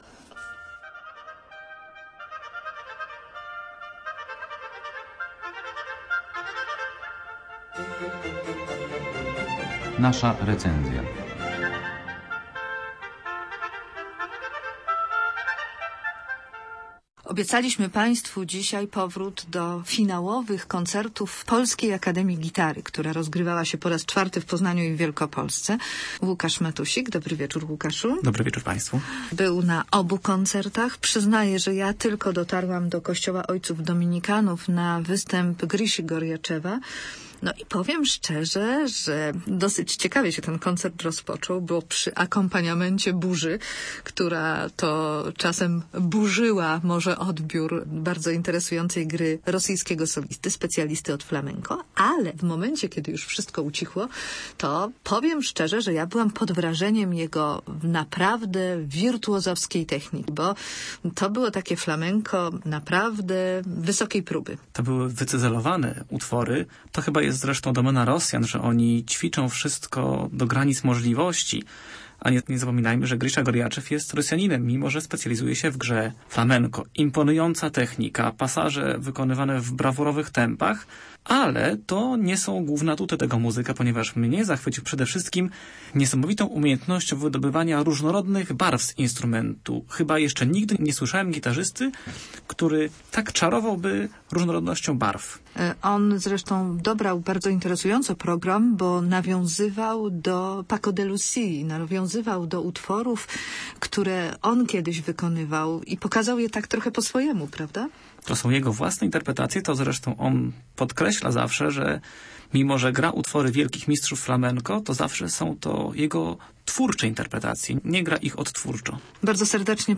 Gitary zagrały pod Ratuszem!